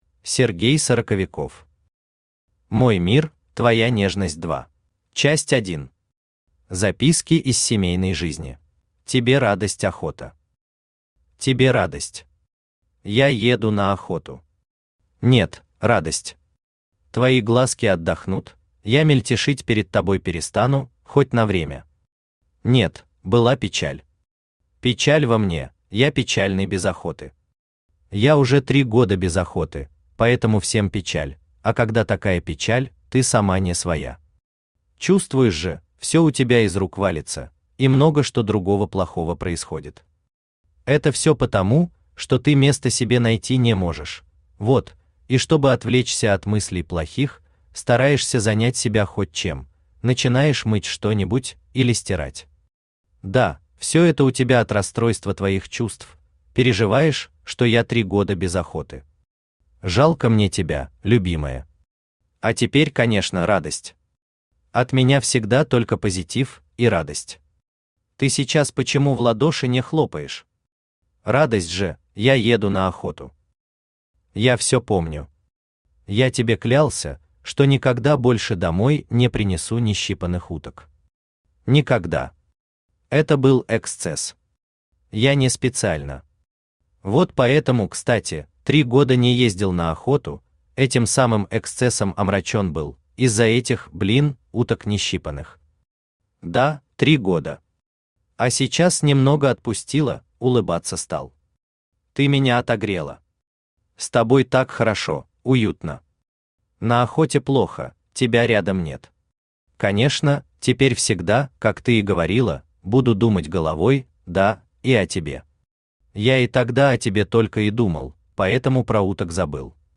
Aудиокнига Мой мир – твоя нежность 2 Автор Сергей Сороковиков Читает аудиокнигу Авточтец ЛитРес.